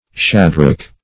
shadrach - definition of shadrach - synonyms, pronunciation, spelling from Free Dictionary
Shadrach \Sha"drach\, n. (Metal.)